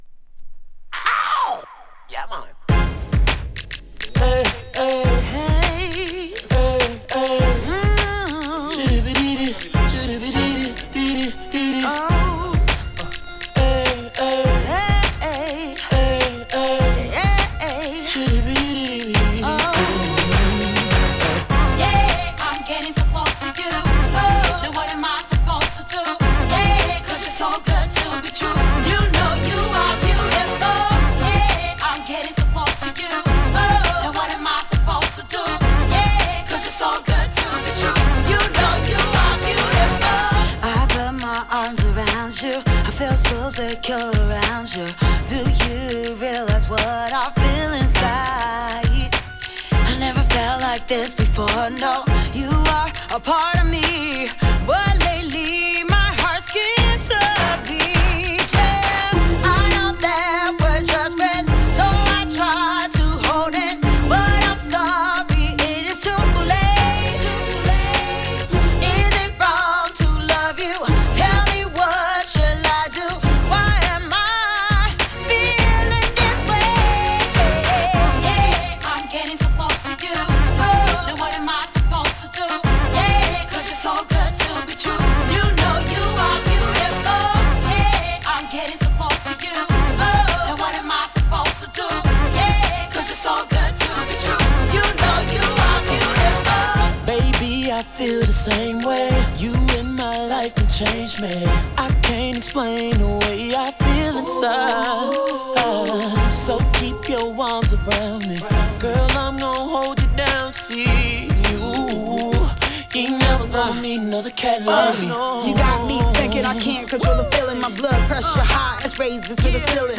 2. > R&B